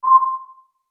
secondTimerSound.wav